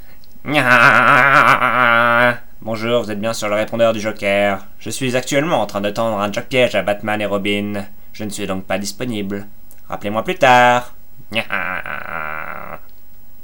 Les Répondeurs téléphoniques
Les répondeurs téléphoniques de vos Bat'héros préférés en mp3!!!!!